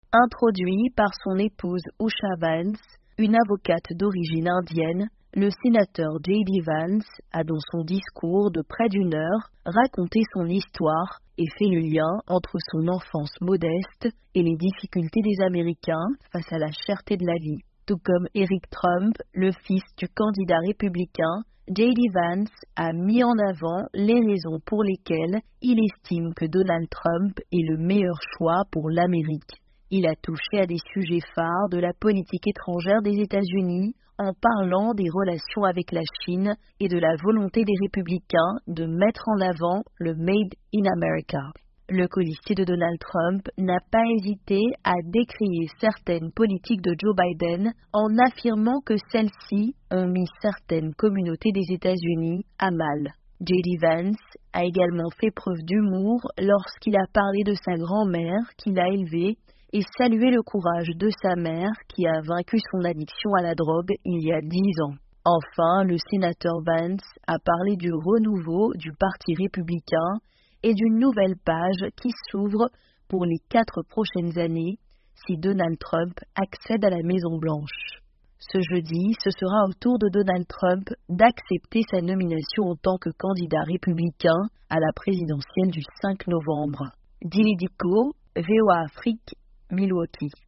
Le sénateur républicain de l’Ohio, J.D. Vance a officiellement accepté sa nomination en tant que colistier de Donald Trump pour la présidence des États-Unis. Dans son discours, il est revenu sur ses origines et a décliné les grandes lignes de sa vision des États-Unis.